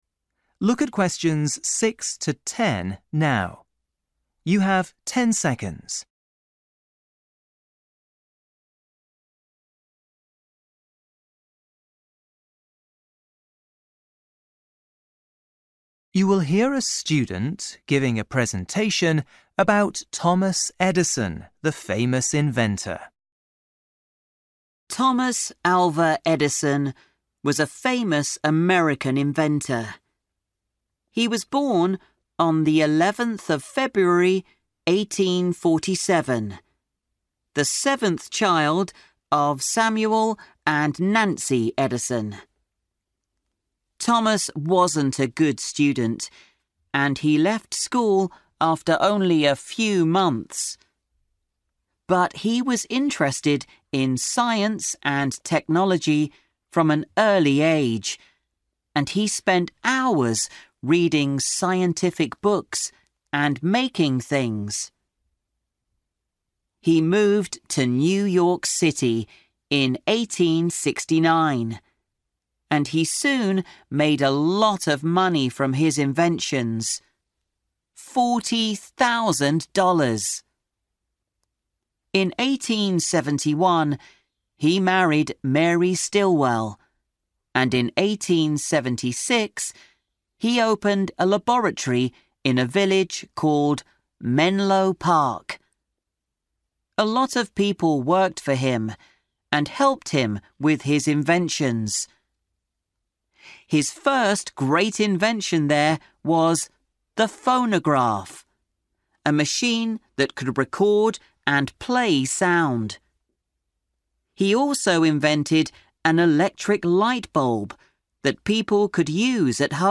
You will hear a student giving a presentation about a famous inventor.